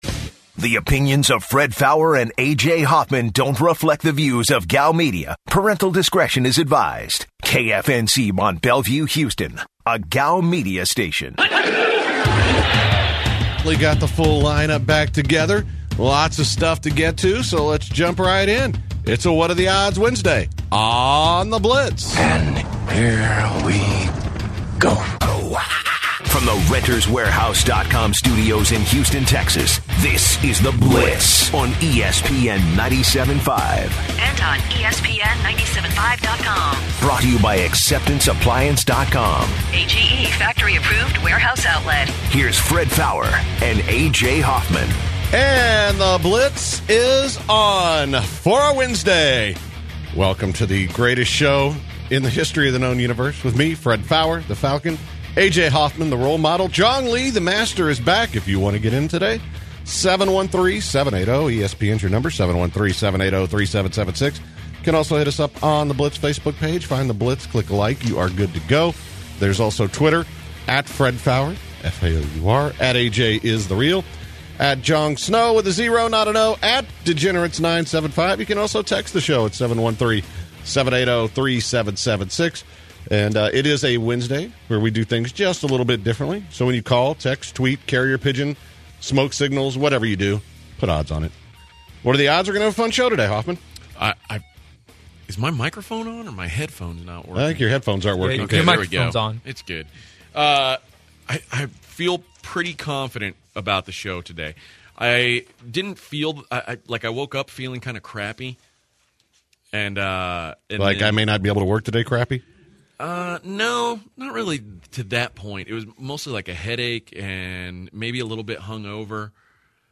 The guys talk Texans heading into their playoff matchup against the Oakland Raiders on Saturday and take calls on other headlines around the NFL.